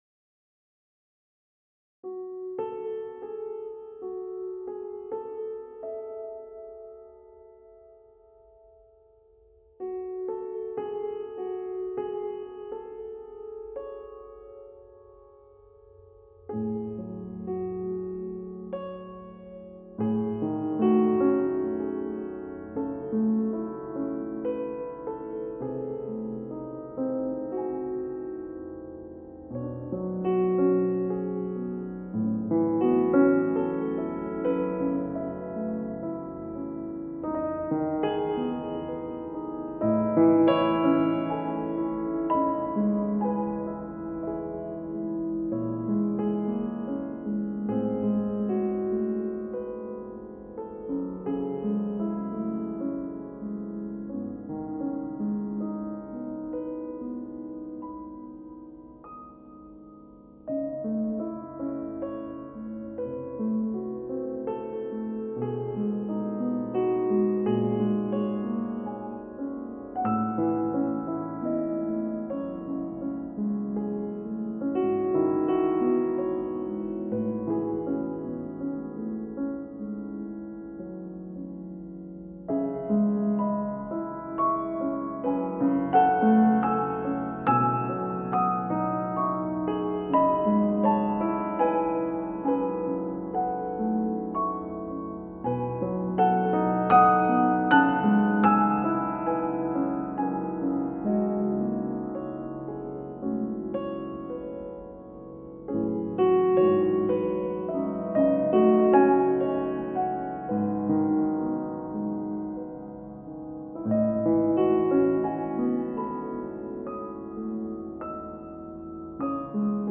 ★★★★★★★★★★★★★★★★★★★★★★★★ ■チェアハウスは《ピアノ即興音楽》以外にも《ストリングス即興音楽》が得意なのです。
ちょっと《ストリングス即興音楽》やってみたら、ものすごく良い音楽が出来ました。